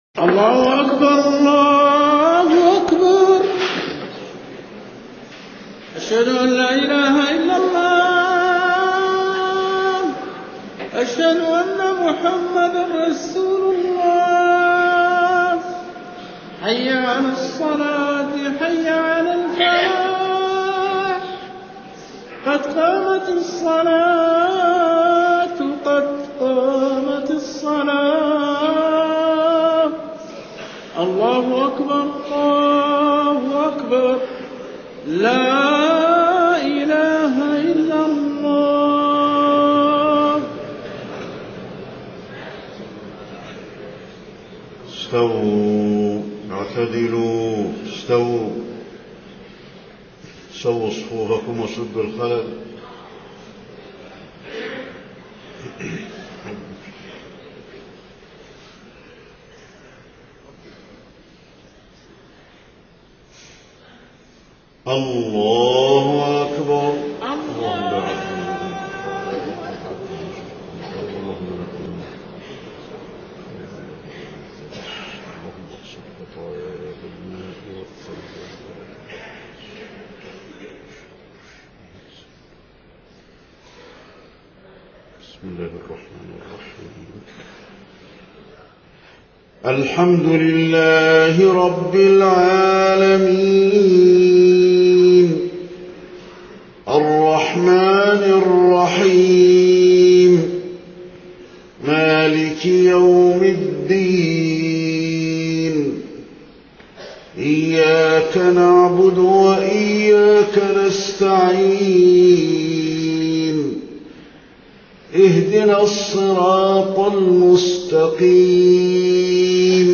صلاة الفجر 30 ذو الحجة 1429هـ سورة الطور كاملة > 1429 🕌 > الفروض - تلاوات الحرمين